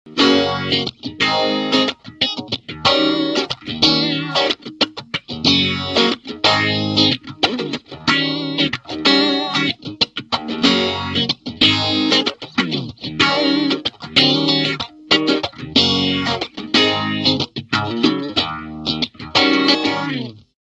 Vintage style Phase Shifter
SE-VPH makes histolical PHASE SHIFTER Sound effect.
You can adjust resonance to control SHARPNESS of Phase Shift sound.
Demo with Single Pickup 1